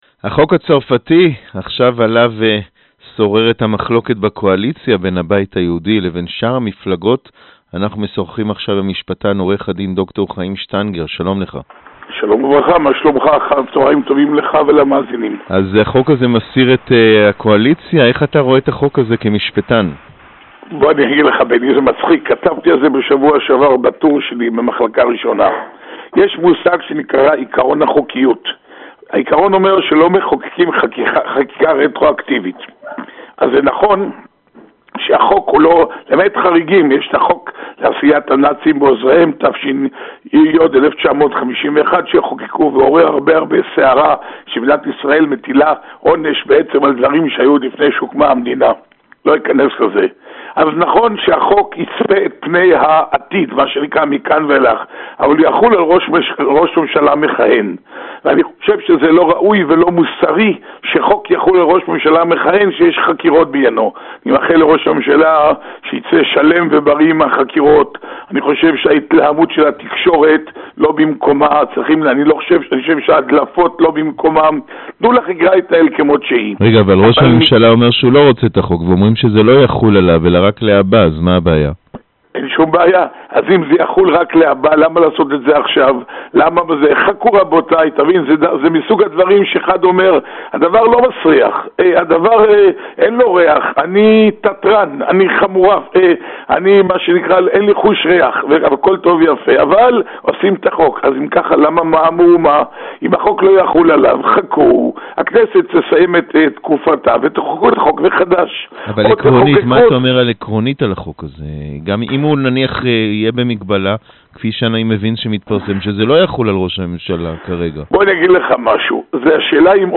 сказал в интервью корреспонденту 7 канала